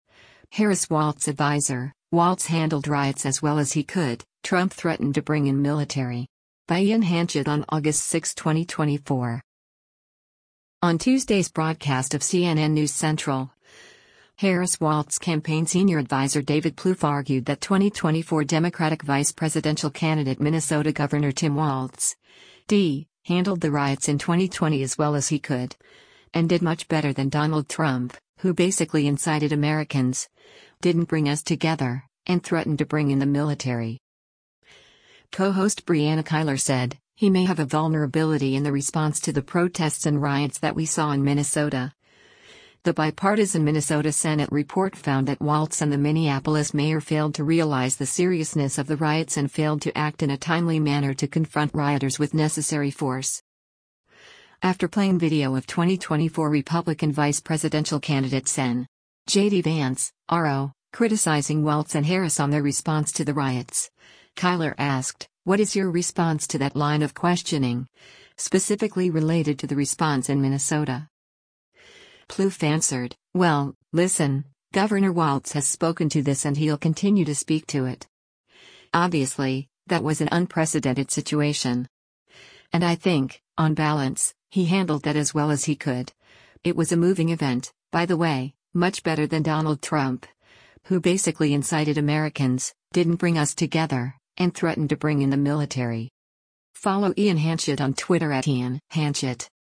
On Tuesday’s broadcast of “CNN News Central,” Harris-Walz Campaign Senior Adviser David Plouffe argued that 2024 Democratic vice presidential candidate Minnesota Gov. Tim Walz (D) handled the riots in 2020 “as well as he could,” and did “much better than Donald Trump, who basically incited Americans, didn’t bring us together, and threatened to bring in the military.”